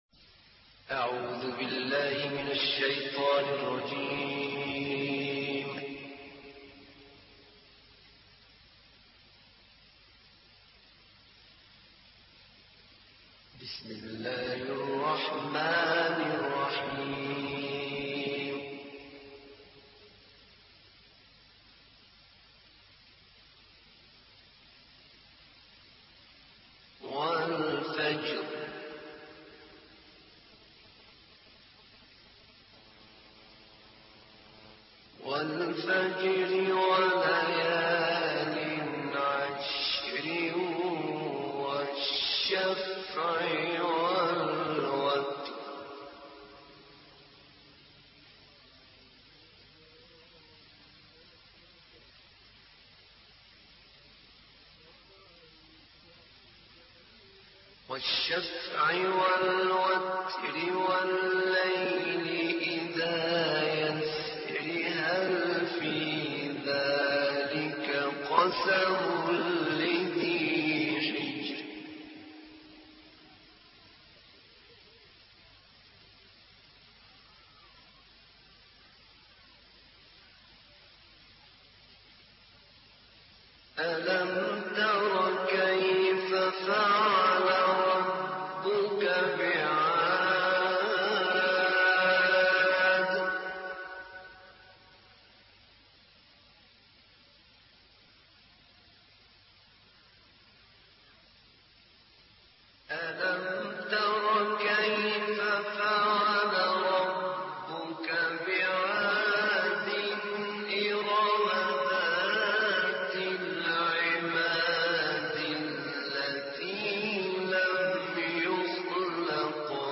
تلاوتی زیبا و ماندگار از استاد شیخ احمد نعینع